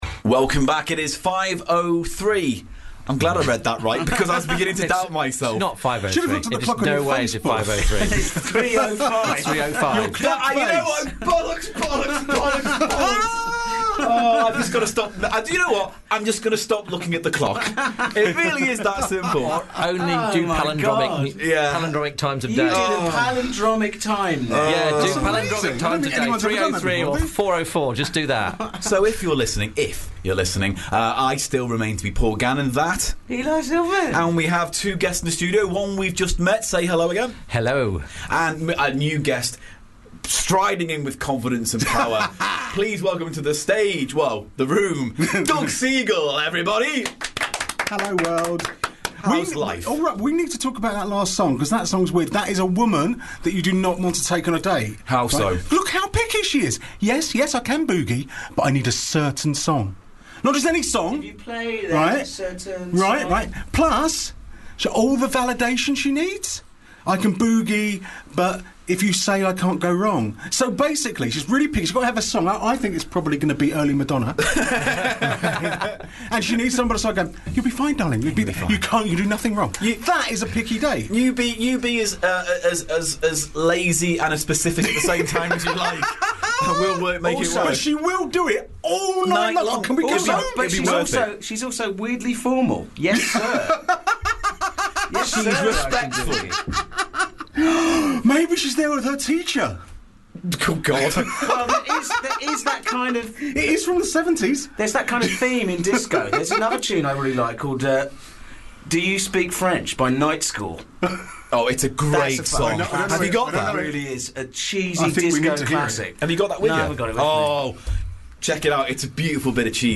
A 27 hour comedy marathon broadcast in aid of Comic Relief! Packed with games, sketches, fund raising challenges and chat.